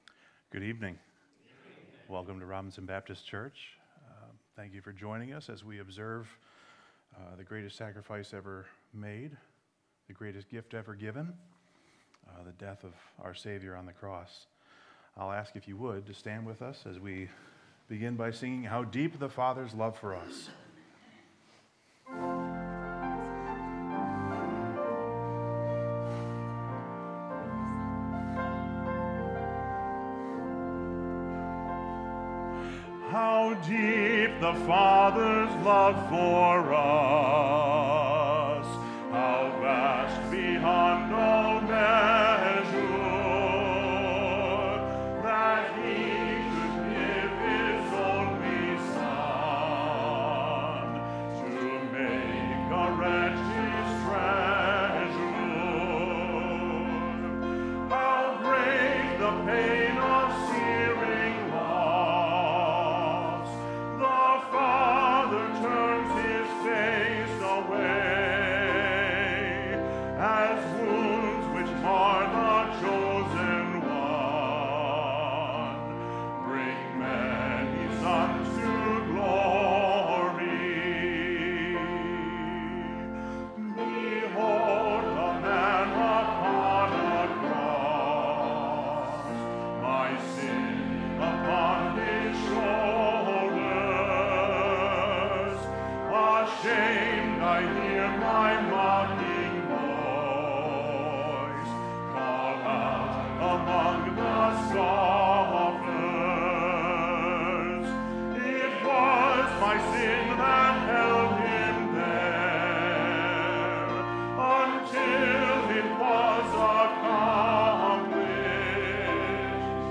Good Friday Service - Robinson Baptist
We are a baptist church located in Grand Haven, MI.